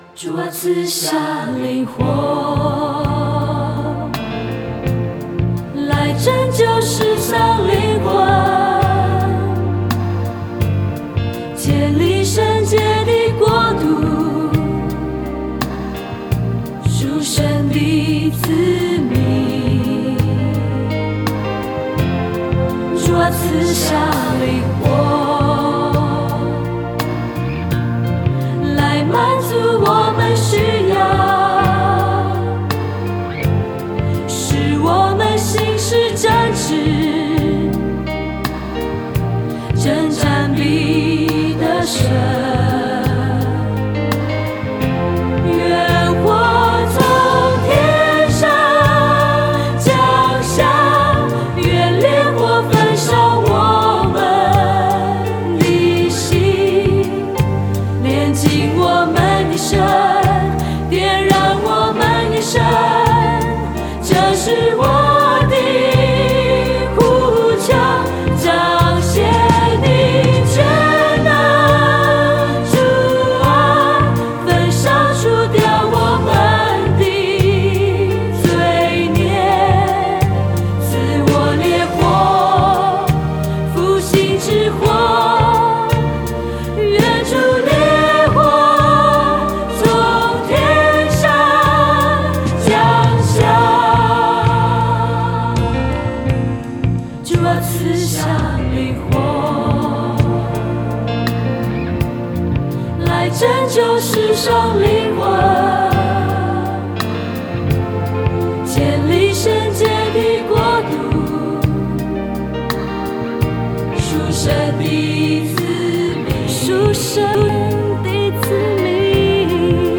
敬拜 091108 上午 10:00